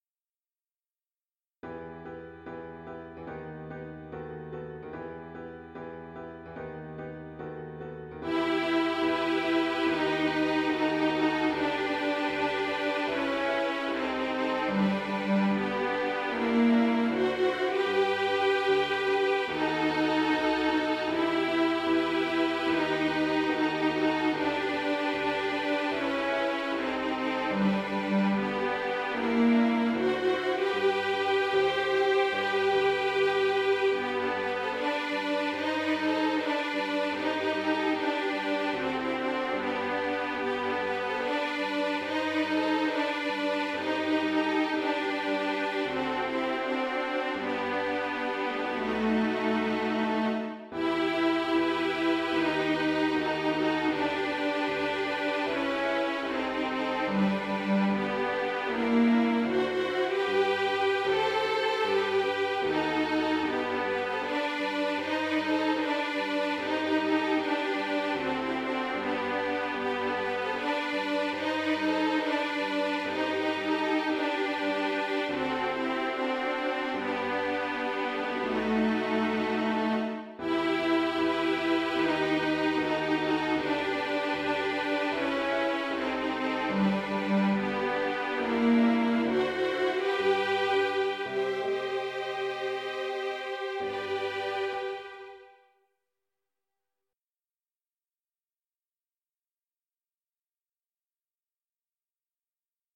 • Alto ONLY